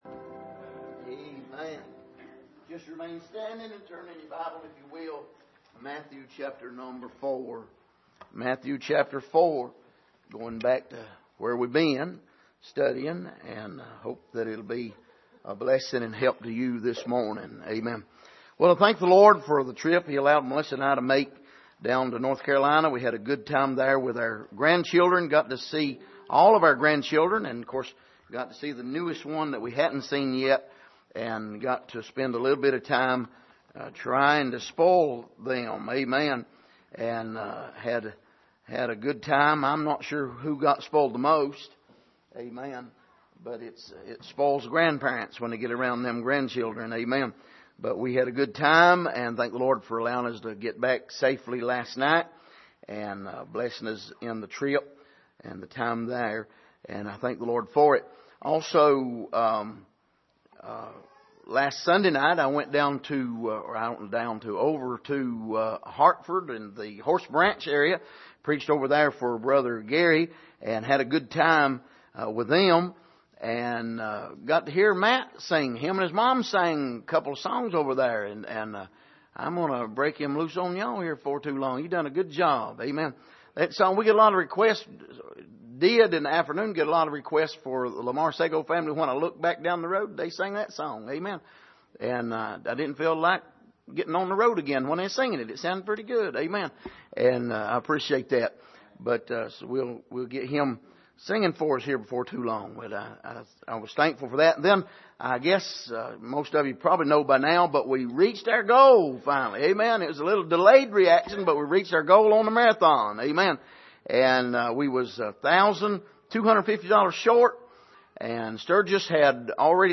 Passage: Matthew 4:1-11 Service: Sunday Evening